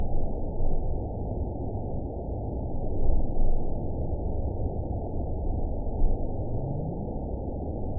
event 920425 date 03/24/24 time 09:38:15 GMT (1 year, 2 months ago) score 9.34 location TSS-AB07 detected by nrw target species NRW annotations +NRW Spectrogram: Frequency (kHz) vs. Time (s) audio not available .wav